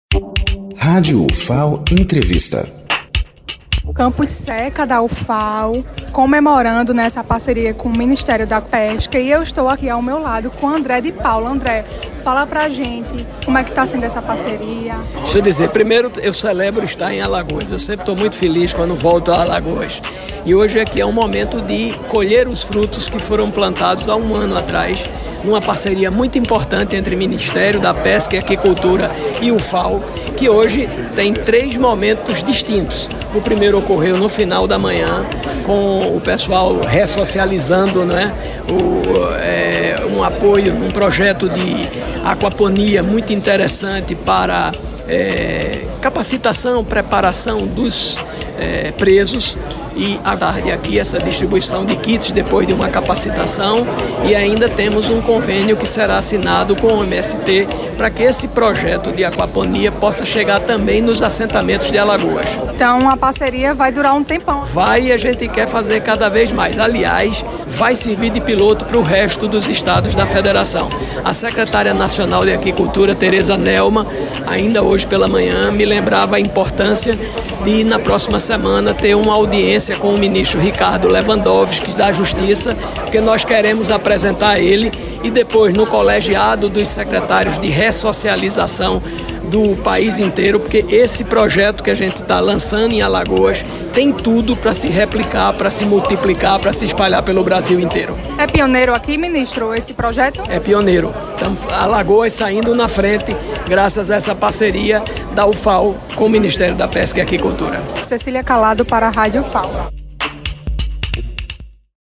Ministro da Pesca e Aquicultura, André de Paula, fala dos investimentos em Alagoas